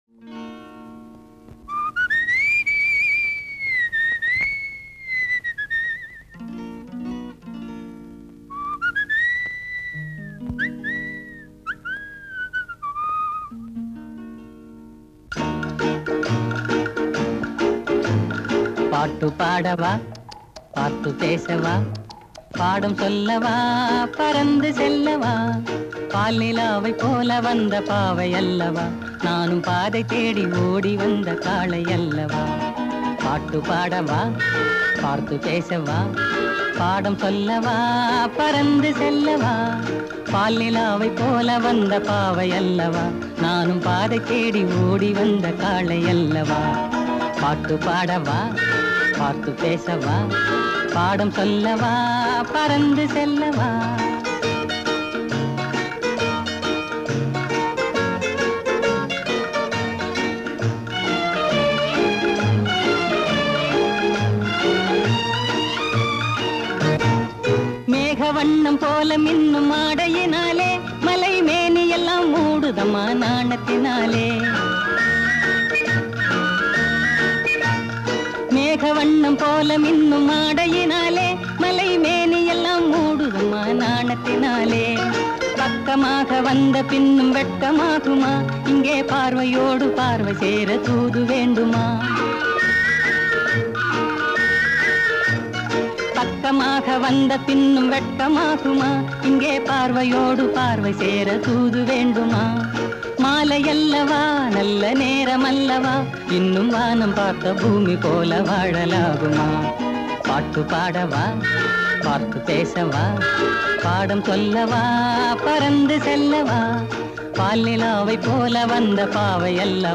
Movie Theme Song
Popular Tamil Song